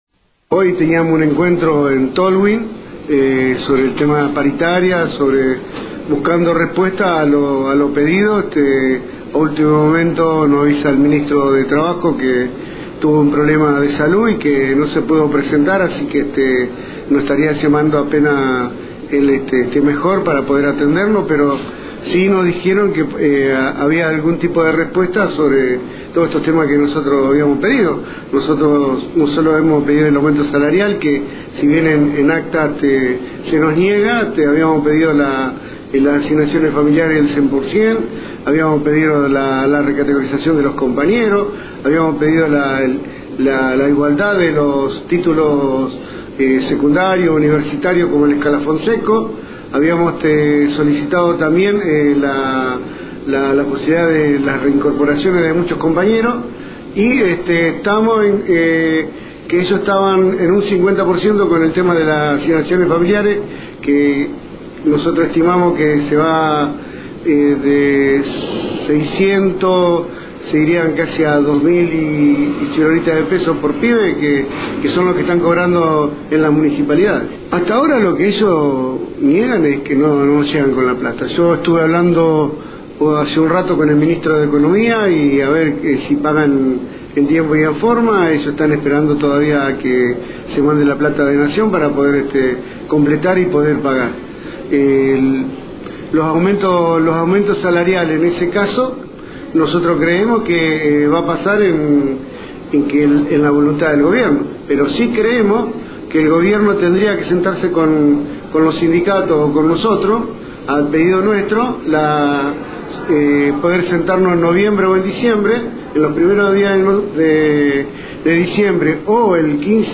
En diálogo con Radio Fueguina